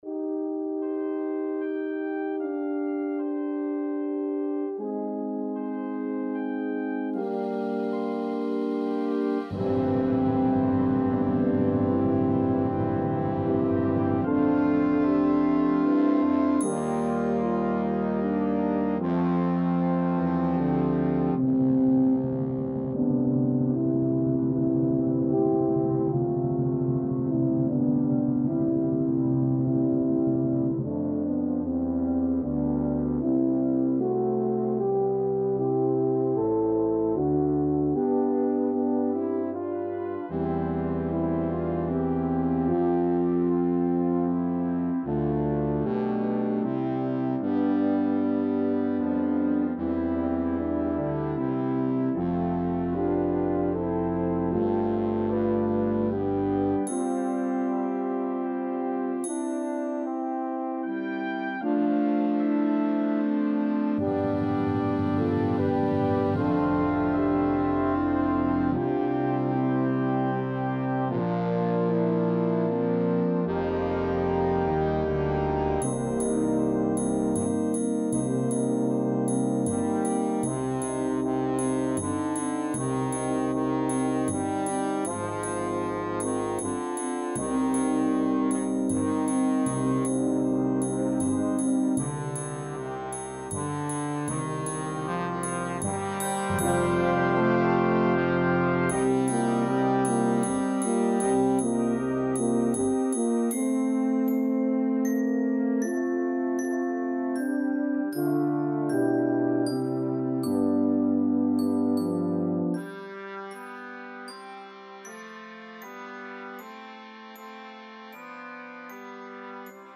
(Hymn Tune Arrangement)